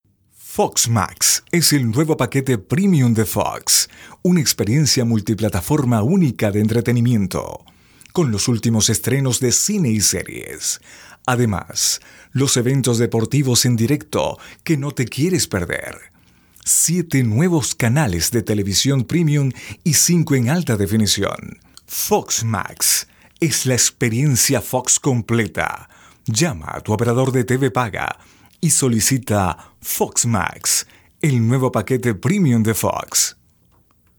Locutor profesional con experiencia de 10 años en conduccion produccion de programas de radio.Experiencia en narracion de documentales, comerciales e identificacion para agencias de publicidad.
spanisch Südamerika
Sprechprobe: eLearning (Muttersprache):